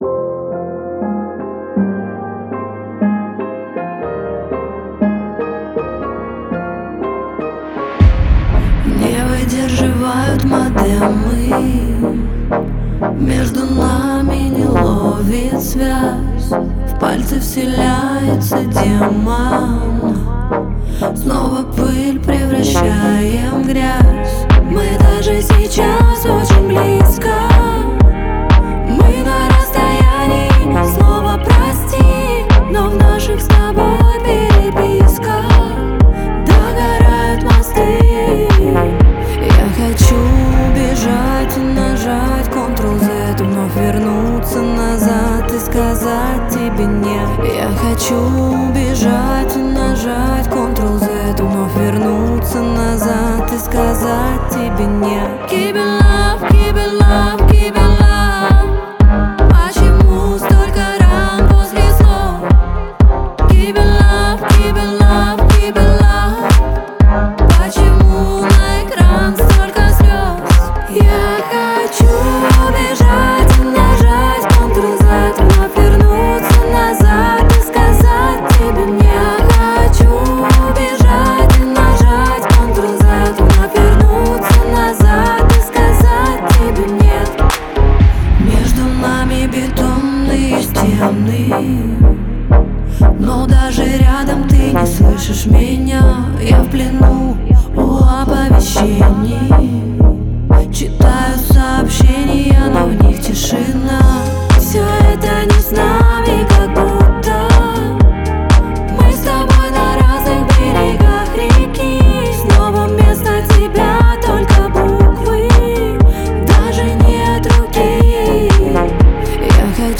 это яркая композиция в жанре электро-поп